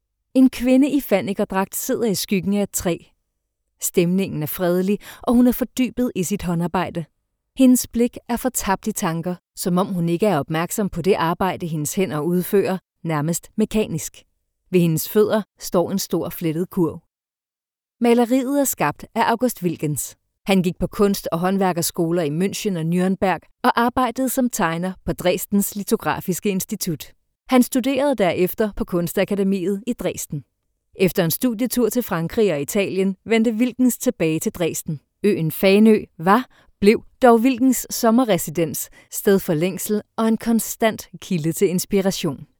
Commercieel, Veelzijdig, Vriendelijk
Corporate